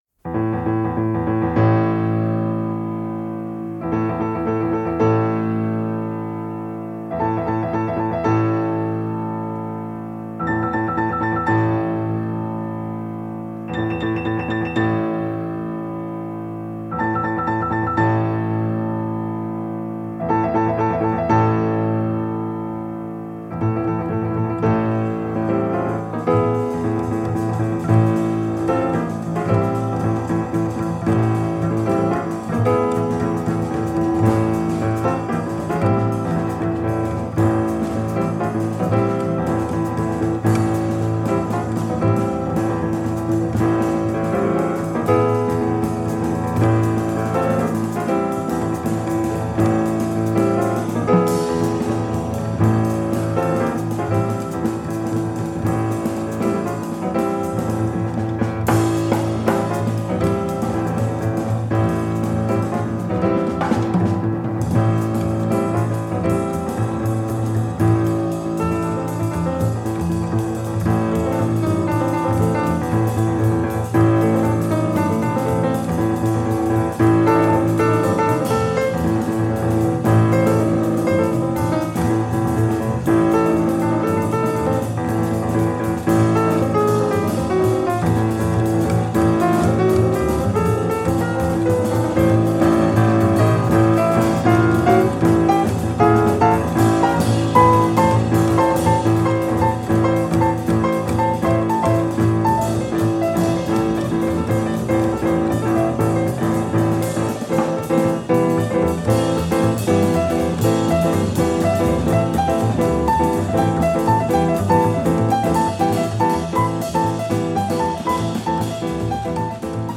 Genre: Jazz
Style: Hard Bop, Contemporary Jazz, Free Jazz